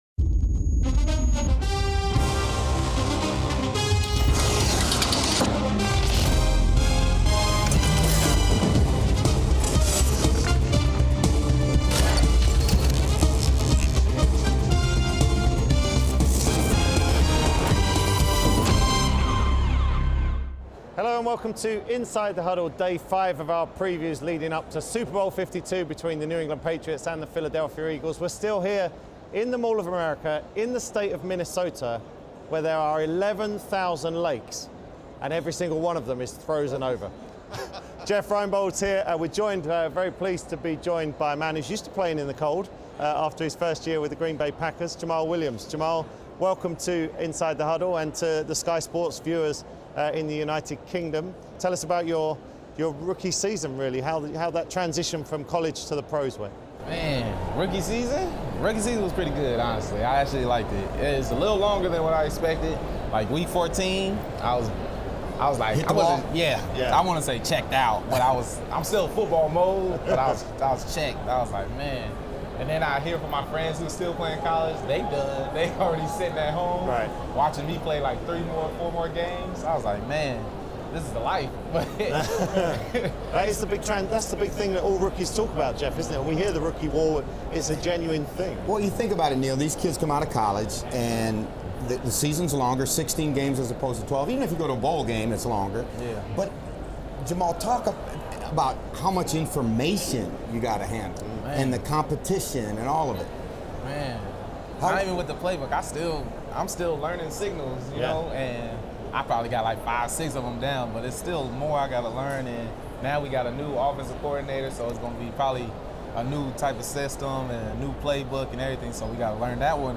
are in Minnesota ahead of Super Bowl LII and are joined by Hall of Fame running back LaDainian Tomlinson.